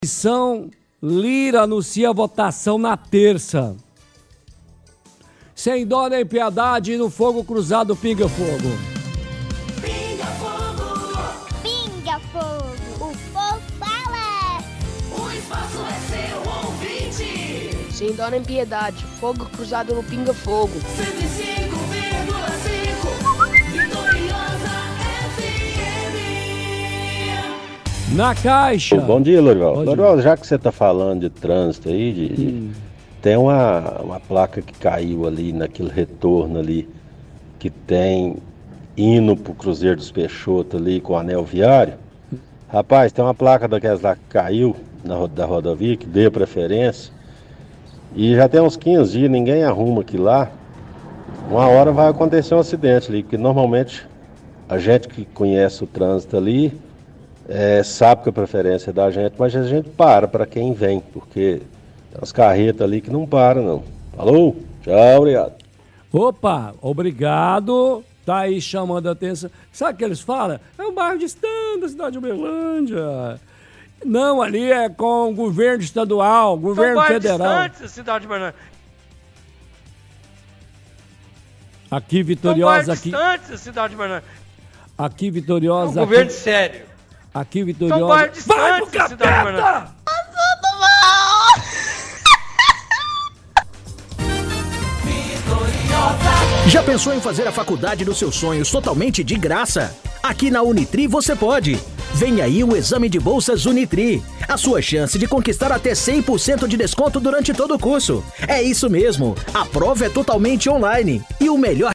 -Ouvinte: Fala de placa que caiu no retorno indo para Cruzeiro dos Peixotos com o Anel Viário.